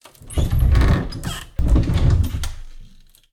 wardrobe1.wav